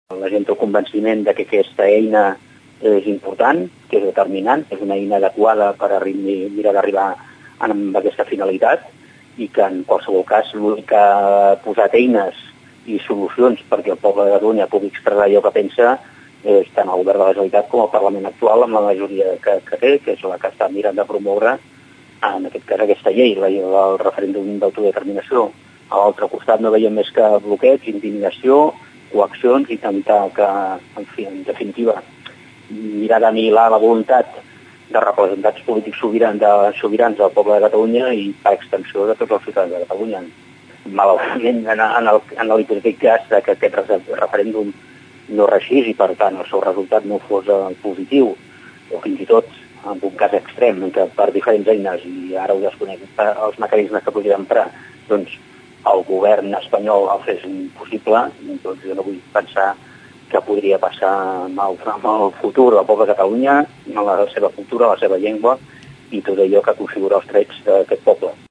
L’escoltem en declaracions a Ràdio Tordera.